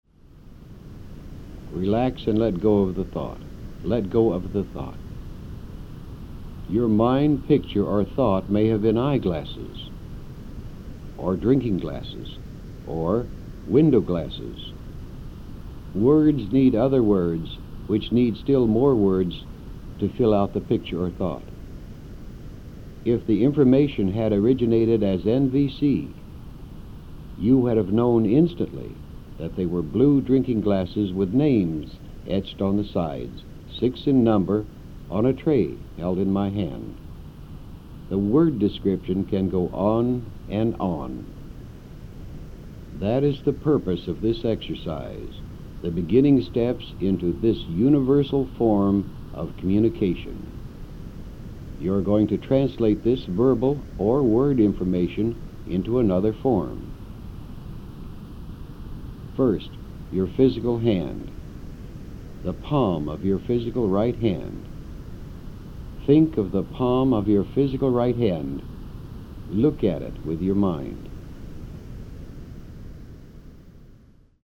Celý název: Gateway Experience Wave IV - Adventure 3 CD (Cesta Bránou Vlna IV - Dobrodružství 3 CD) Verbální vedení: Anglické verbální vedení namluvil Robert Monroe.